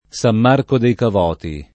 Sam m#rko dei kav0ti] (Camp.), San Marco in Lamis [Sam m#rko in l#miS] (Puglia) — sim. i cogn.